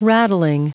Prononciation du mot rattling en anglais (fichier audio)
Prononciation du mot : rattling